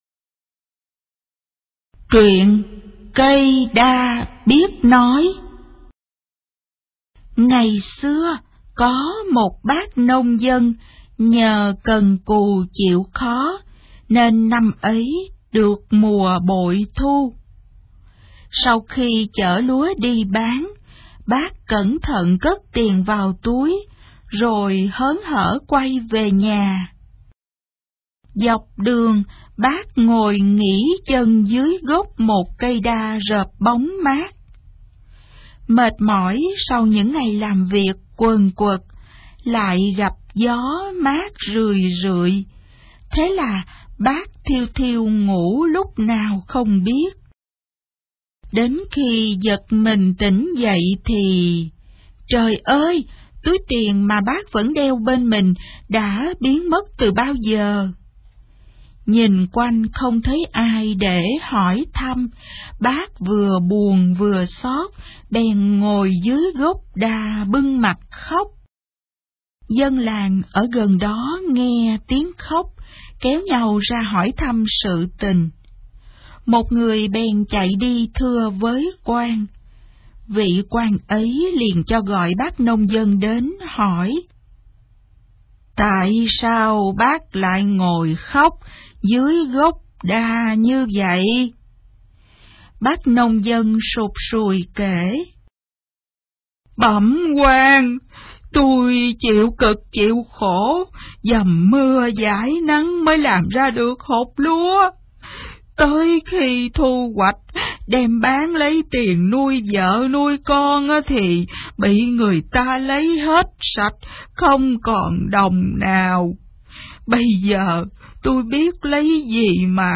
Sách nói | Nai Ngọc